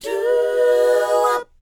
DOWOP C#GU.wav